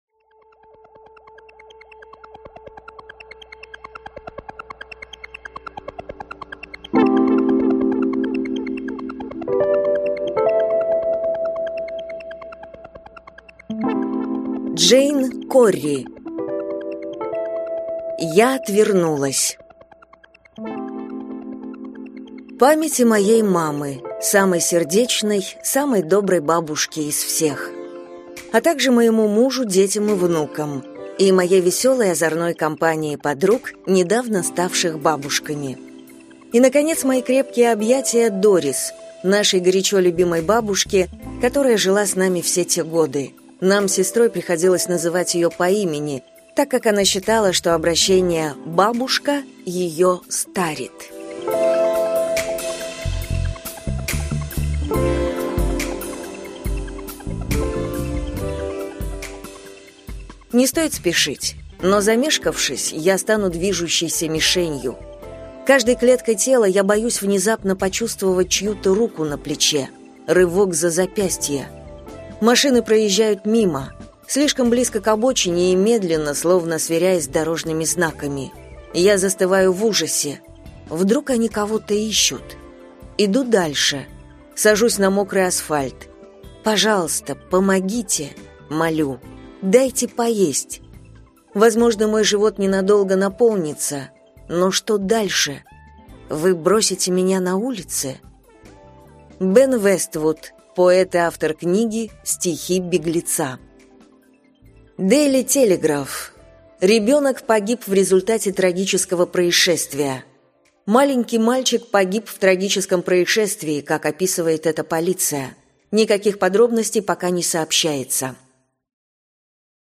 Аудиокнига Я отвернулась | Библиотека аудиокниг